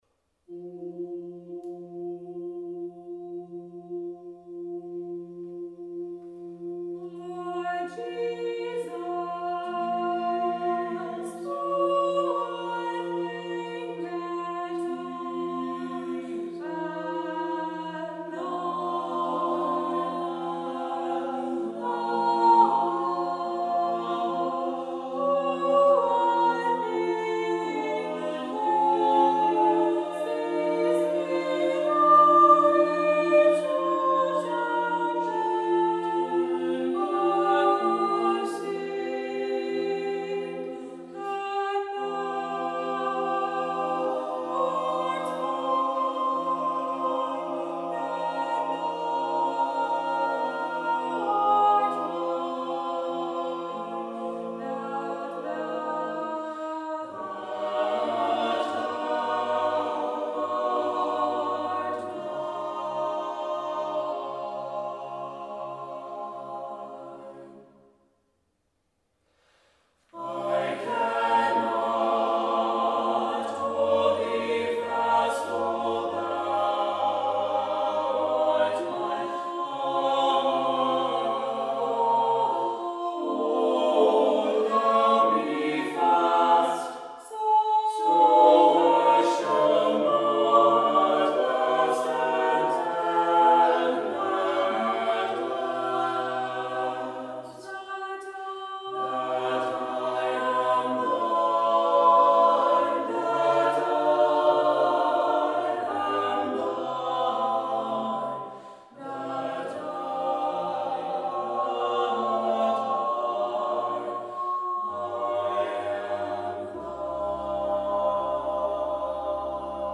This is the choir arrangement.
This piece is written for SATB choir with Soprano soloist.